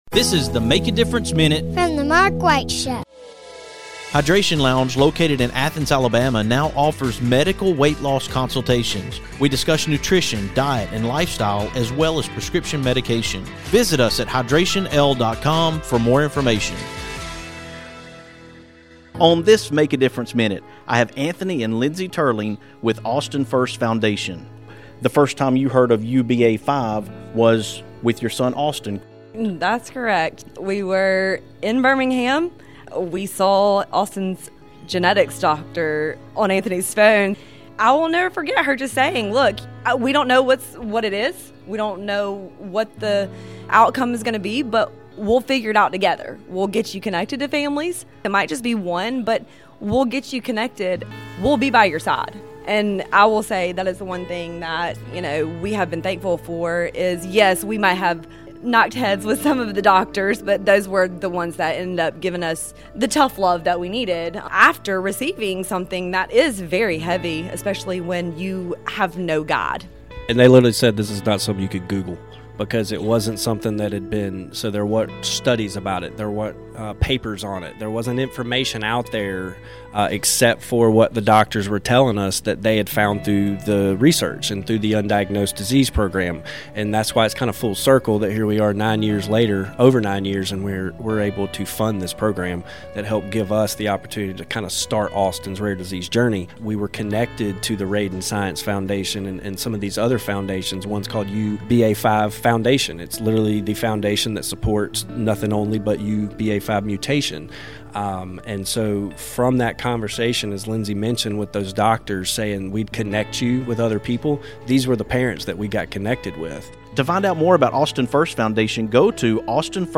On this MADM, we are coming to you from Opelika, Alabama